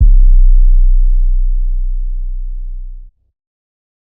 808_Cassius Jay Round 808.wav